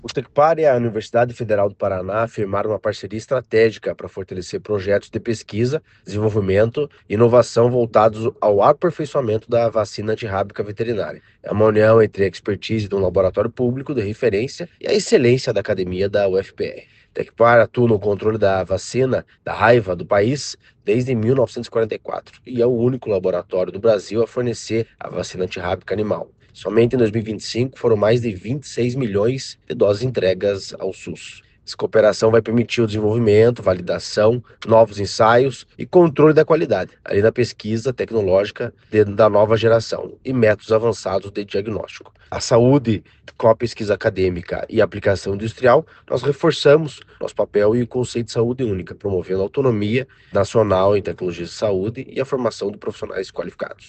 Sonora do diretor-presidente do Tecpar, Eduardo Marafon, sobre a parceria do instituto com a UFPR para produção de vacina antirrábica veterinária